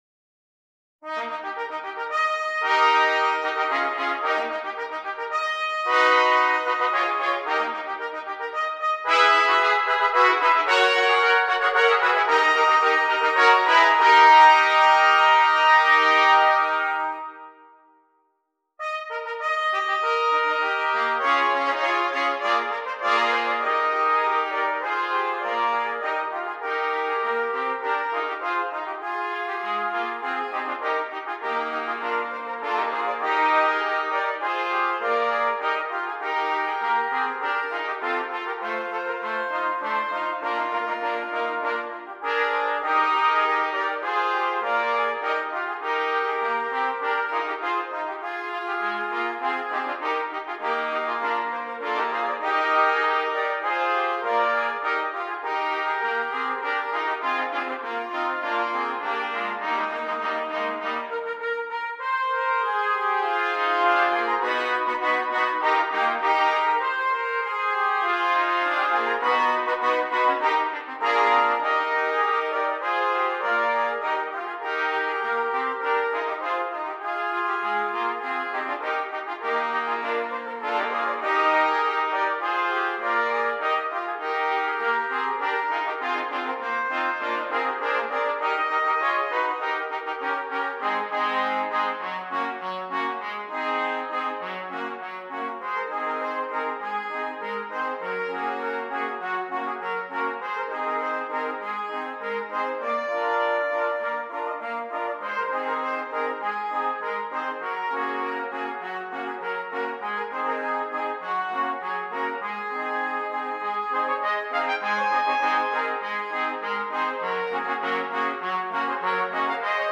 Brass
8 Trumpets
This work, for 8 trumpets, is showy and fun to play.